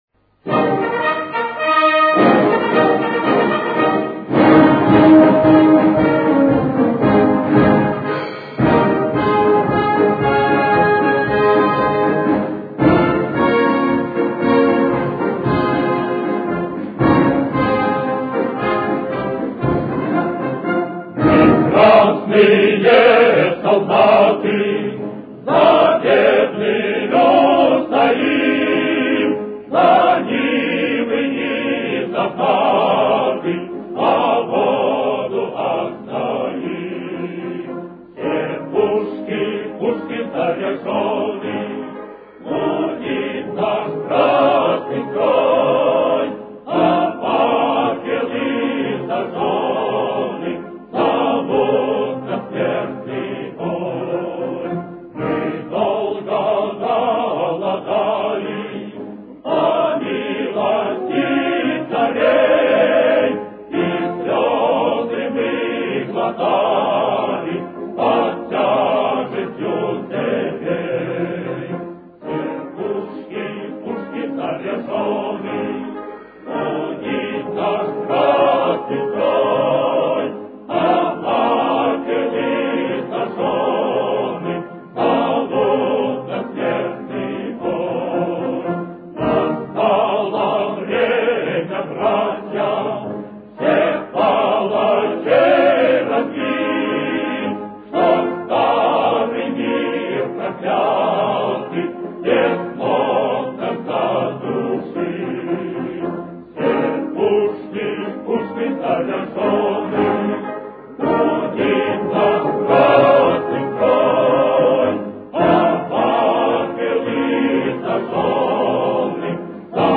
Ля-бемоль мажор. Темп: 115.